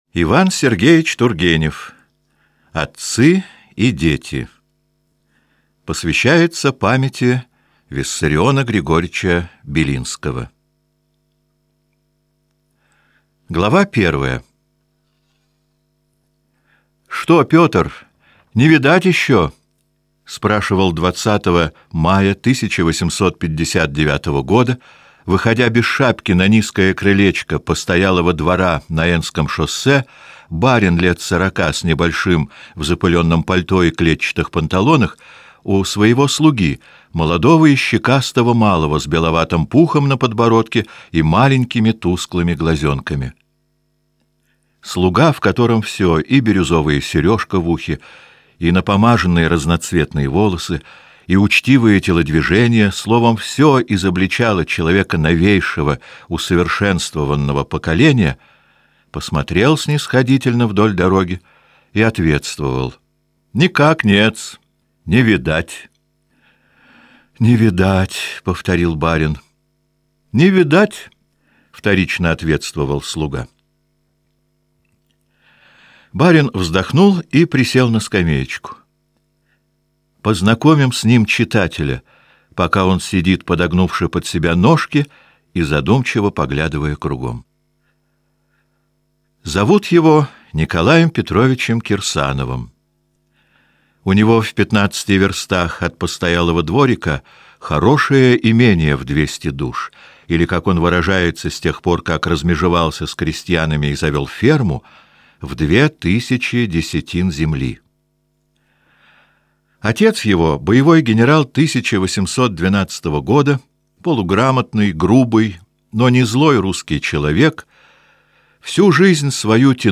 Аудиокнига Отцы и дети. Ася | Библиотека аудиокниг